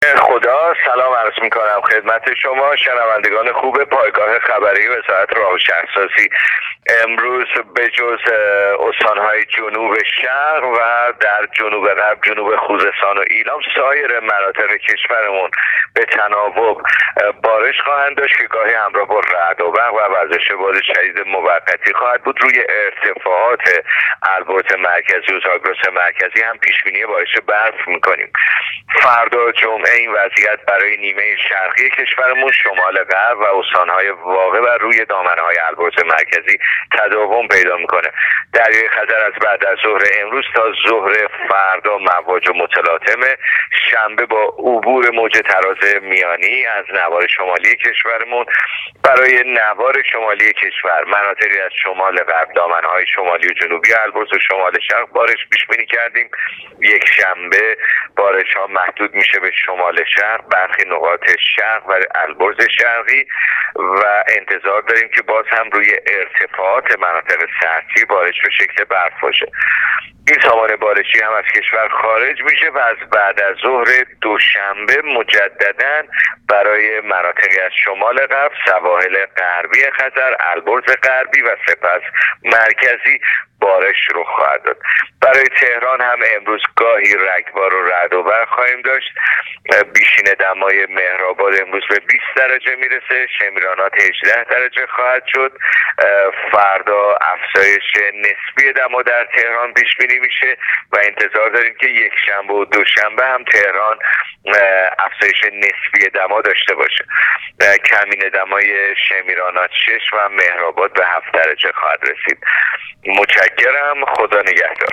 گزارش رادیو اینترنتی از آخرین وضعیت آب و هوای چهاردهم فروردین ۱۳۹۹